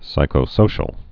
(sīkō-sōshəl)